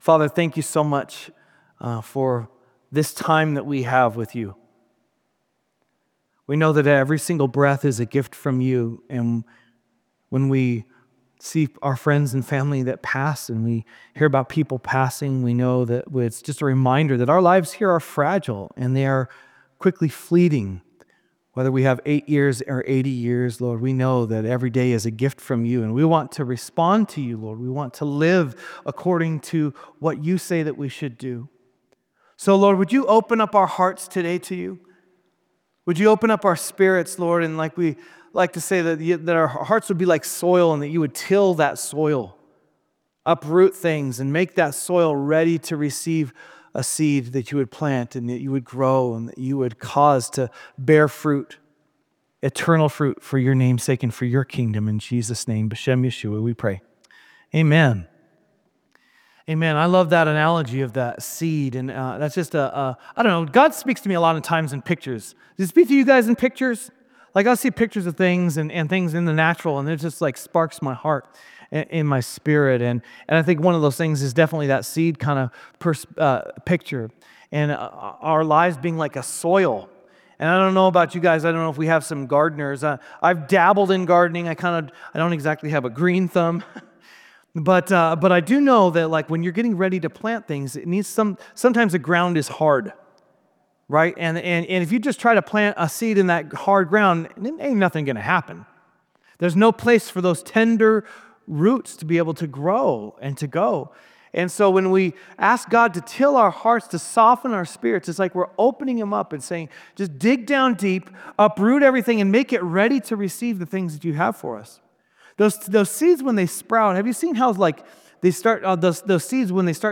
Drawing from Matthew 22 and 28, he reminds us that the mission of the church is not a suggestion—it’s a divine assignment. This message will reignite your passion for the Great Commandment and the Great Commission, revealing that every believer is called to be a co-laborer with Christ.